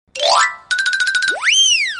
Cartoon Running Sound Effect Free Download
Cartoon Running